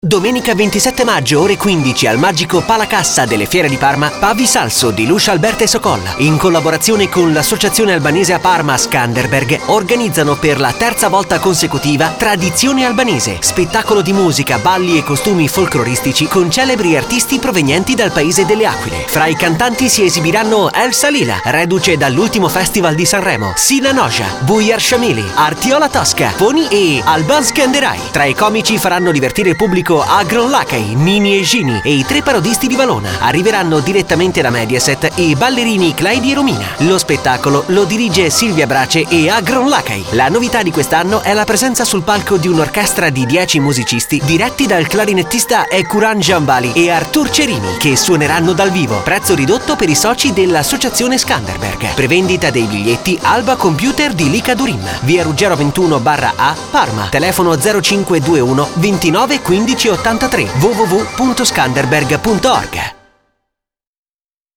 Spot " Radio Malvisi" 87.50 MHz